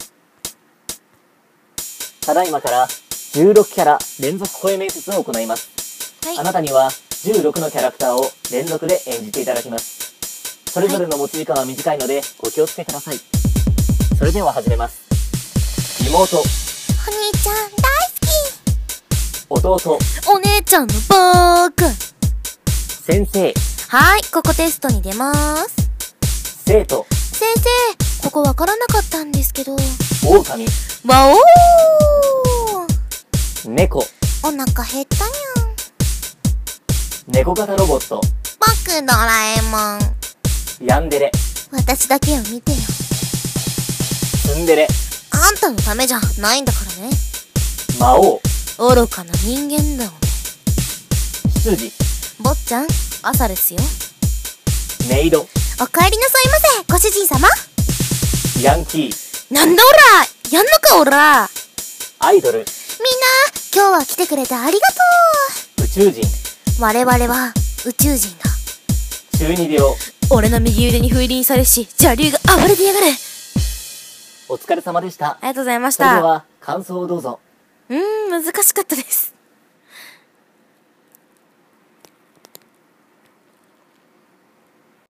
16キャラ連続声面接